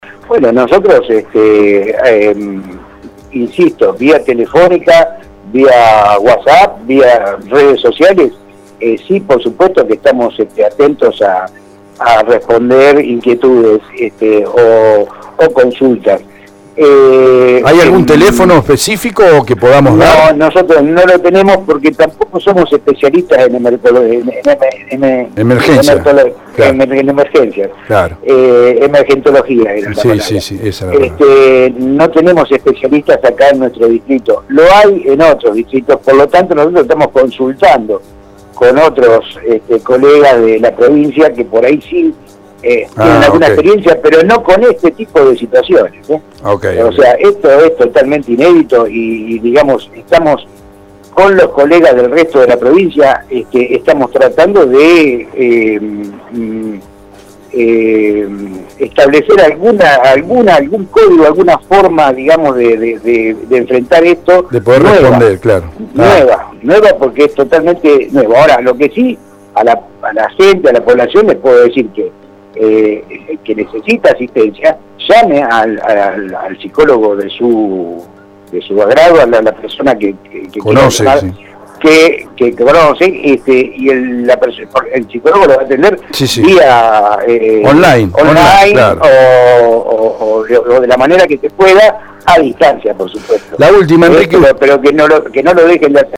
Interesante entrevista de este martes